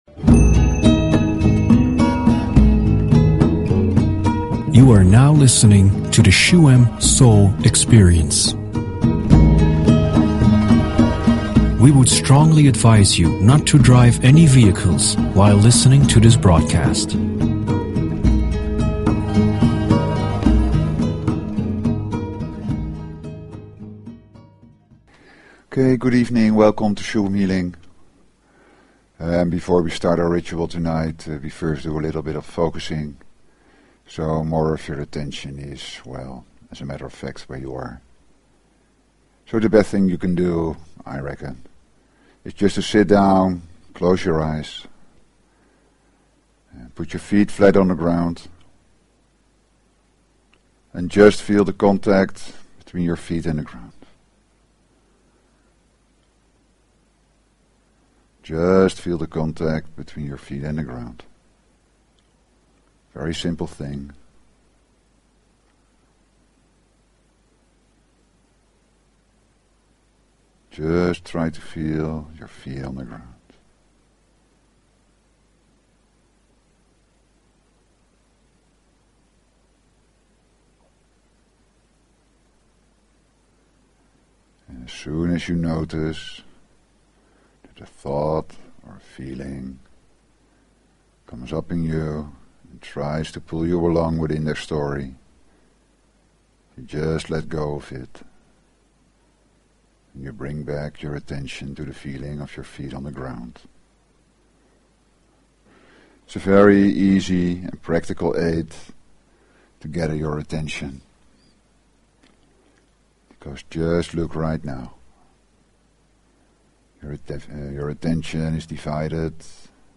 Talk Show Episode, Audio Podcast, Shuem_Soul_Experience and Courtesy of BBS Radio on , show guests , about , categorized as
During the shamanic Healingmeditation you listen to the sounds of a shaman's drum, rattle and chant.